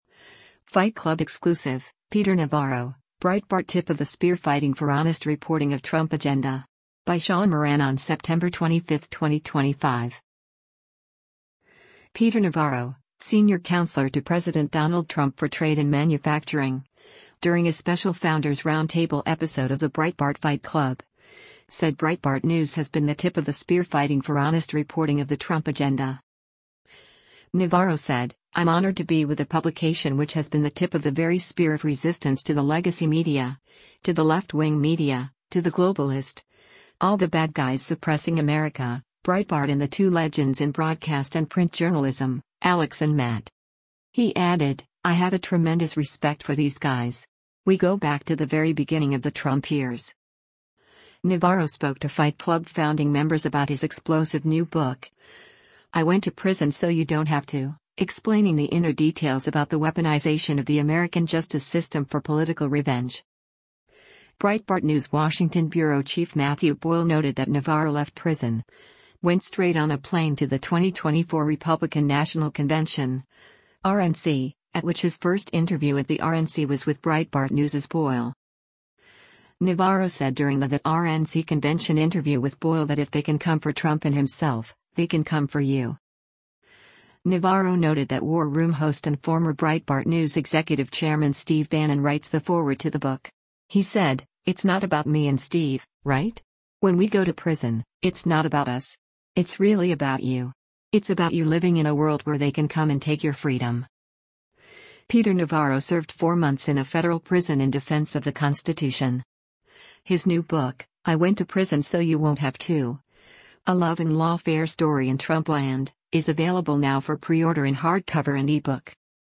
Peter Navarro, senior counselor to President Donald Trump for trade and manufacturing, during a special Founders Roundtable episode of the Breitbart Fight Club, said Breitbart News has been the “tip of the spear” fighting for honest reporting of the Trump agenda.